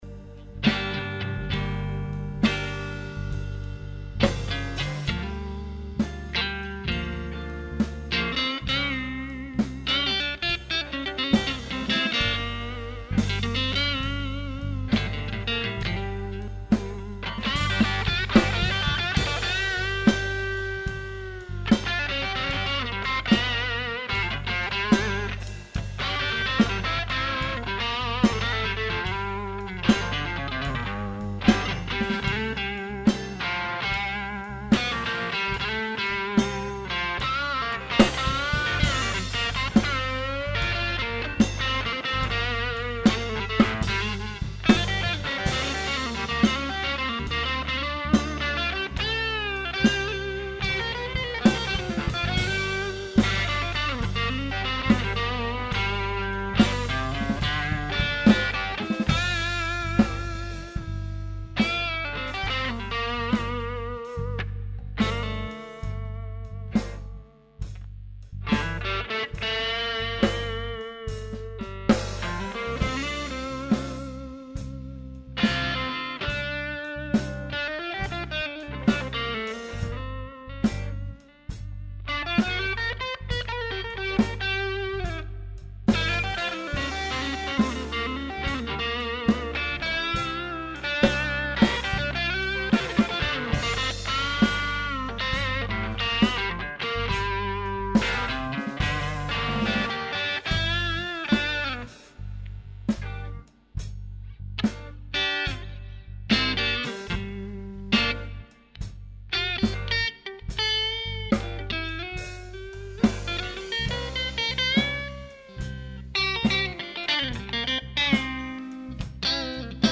-Preamp a lampe 2x 12ax7, midi
-son chaud et precis
BluesMix1.mp3